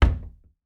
Close Cabinet Door Sound
household
Close Cabinet Door